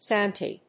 /ˈsænti(米国英語), ˈsænti:(英国英語)/